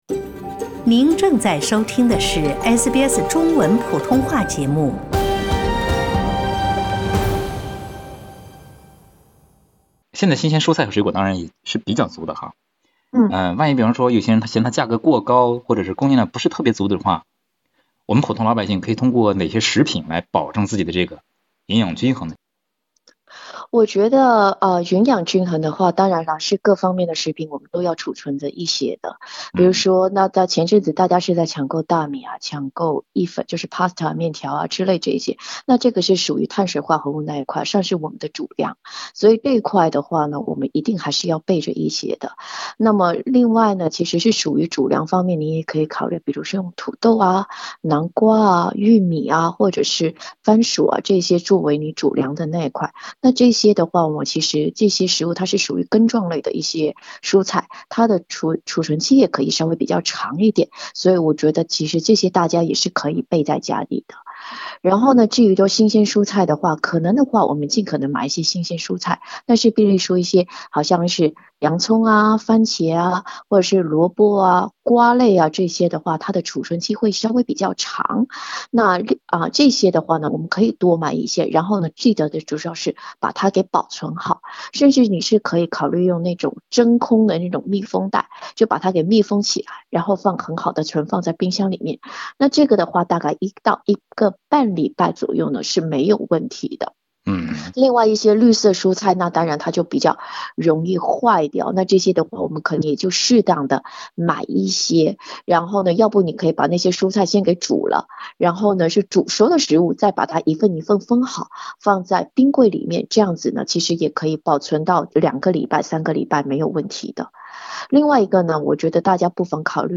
点击上方图片收听采访录音。